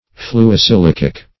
Fluosilicic \Flu`o*si*lic"ic\, a. [Fluo- + silicic: cf. F.
fluosilicic.mp3